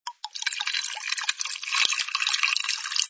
sfx_drinks_pouring06.wav